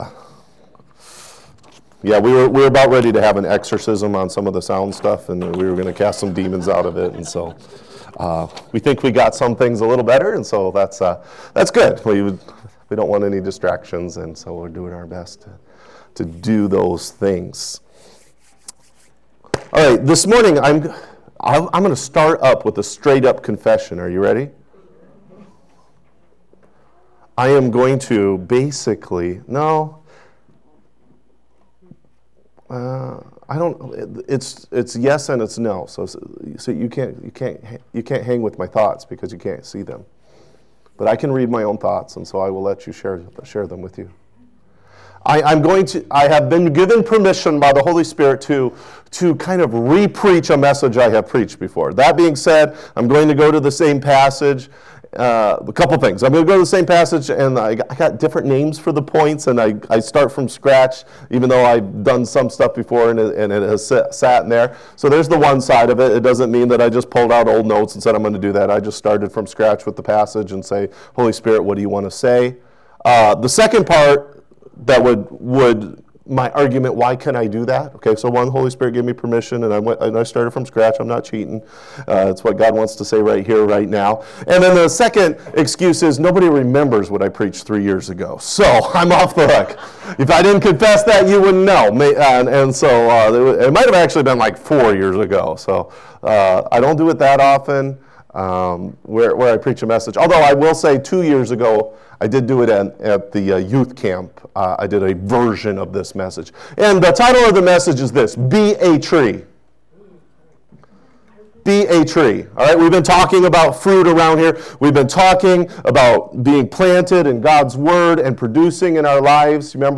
Sermon Archive | Life Worship Center